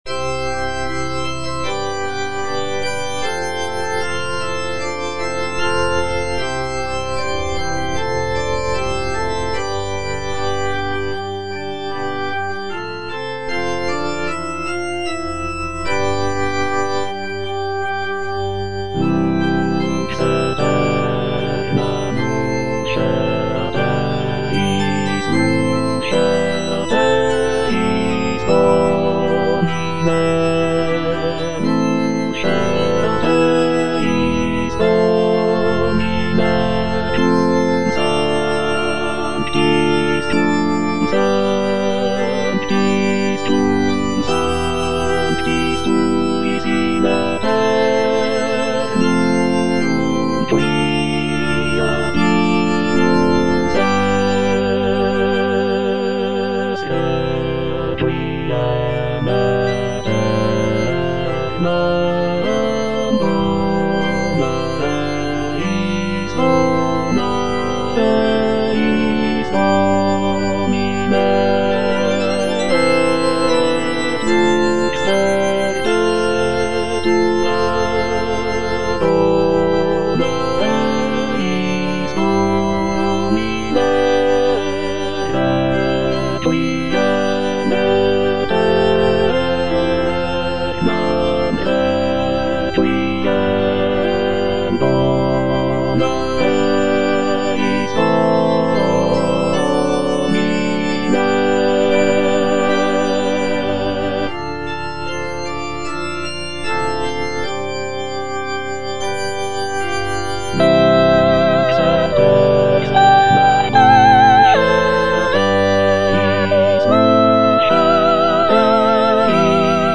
Soprano (Emphasised voice and other voices) Ads stop
is a sacred choral work rooted in his Christian faith.